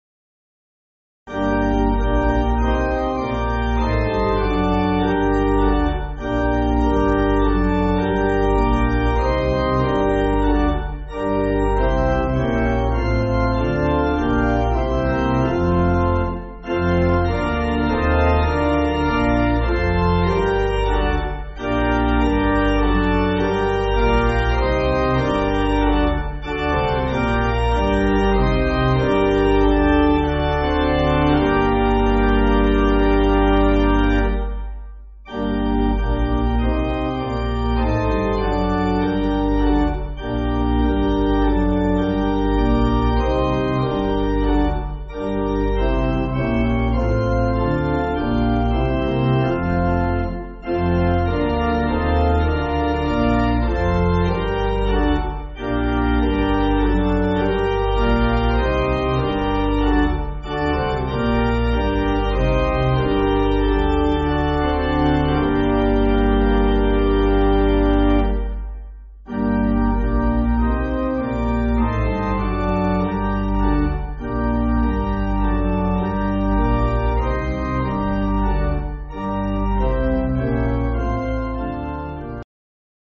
Organ
(CM)   4/Gm